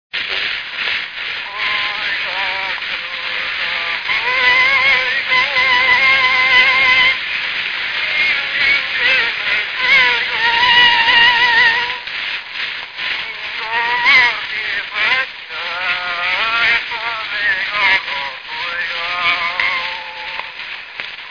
Moldva és Bukovina - Moldva - Bogdánfalva
Stílus: 7. Régies kisambitusú dallamok
Szótagszám: 6.6.6.6
Kadencia: 5 (4) 1 1